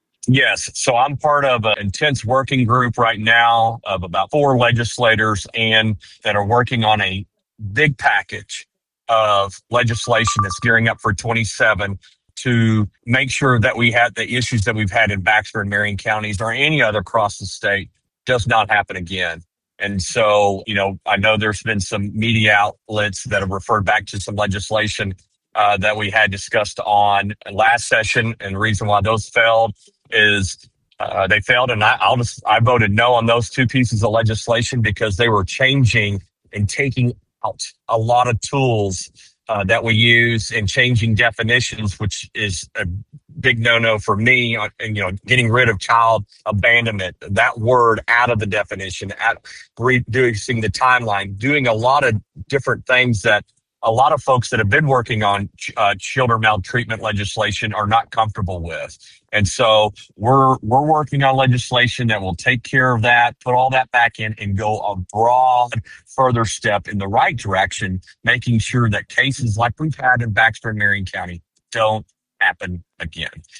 KTLO, Classic Hits and the Boot News spoke with Painter, who says the goal is to strengthen child welfare laws and ensure similar situations do not happen again.